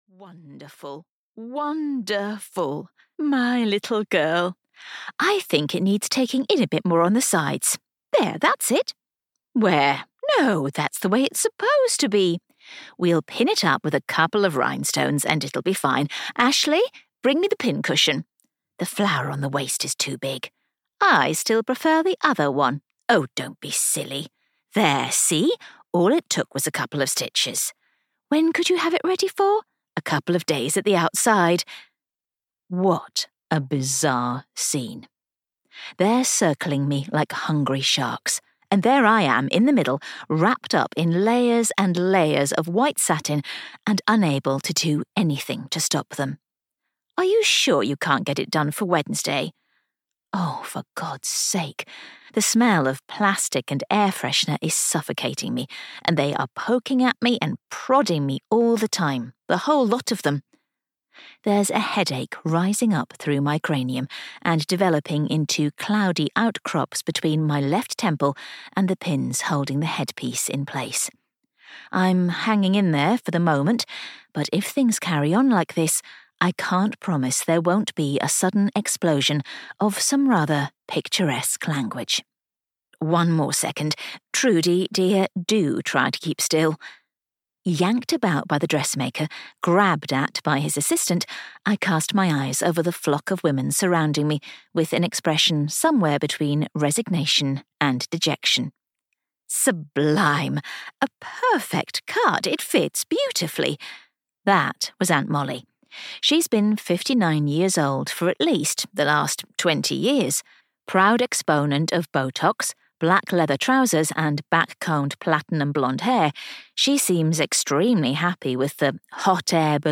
The Difference Between You & Me (EN) audiokniha
Ukázka z knihy